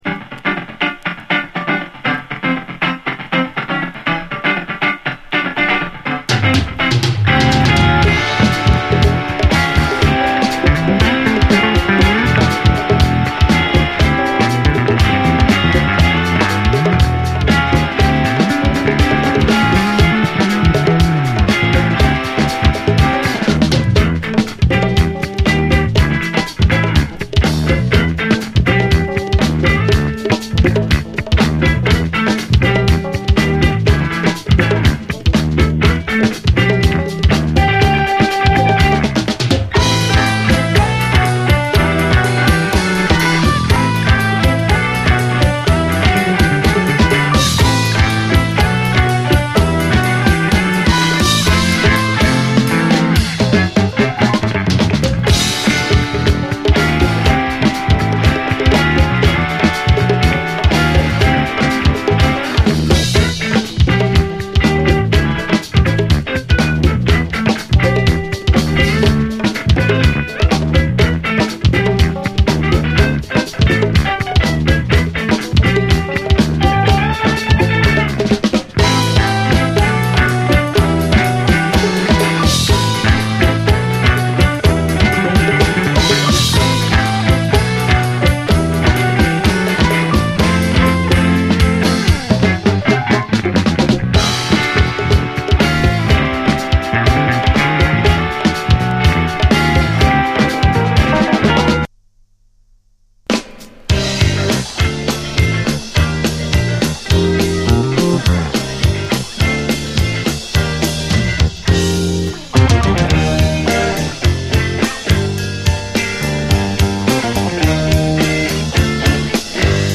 深い味わいを誇るゴージャス極まる一曲！